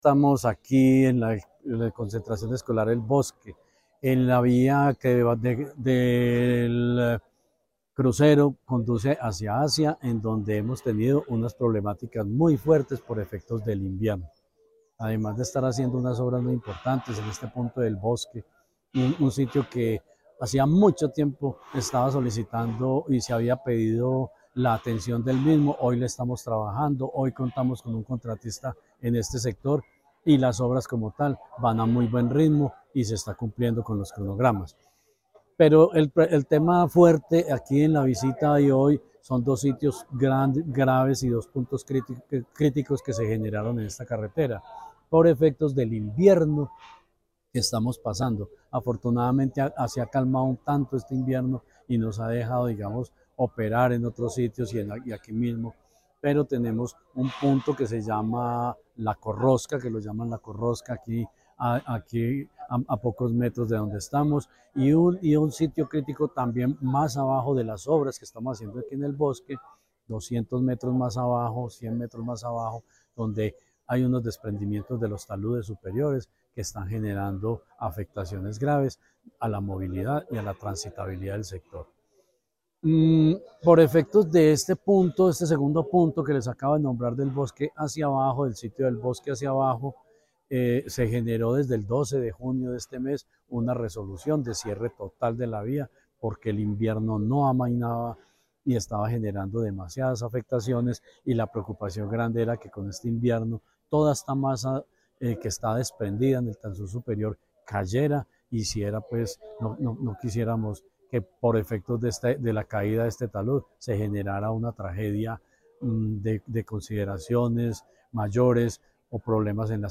Secretario de Infraestructura, Jorge Ricardo Gutiérrez Cardona.